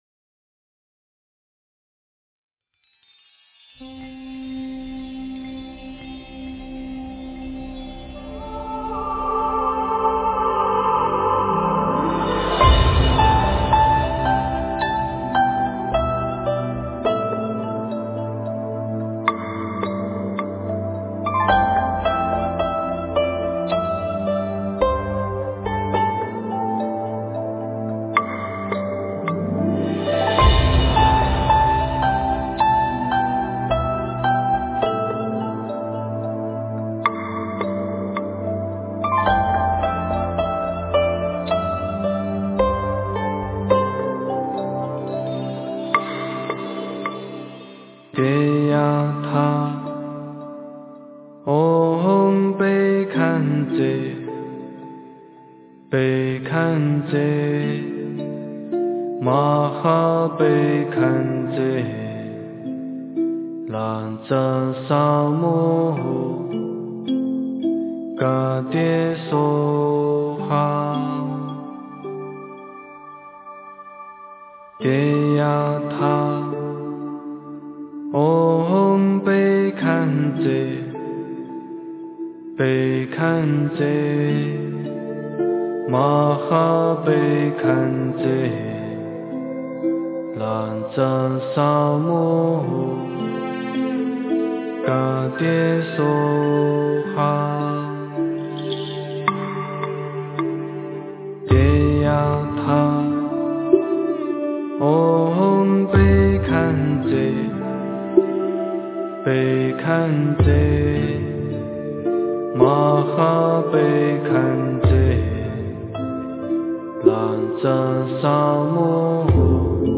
诵经
佛音 诵经 佛教音乐 返回列表 上一篇： 般若心咒 下一篇： 药师佛心咒 相关文章 四大祝延--普陀山梵唄 四大祝延--普陀山梵唄...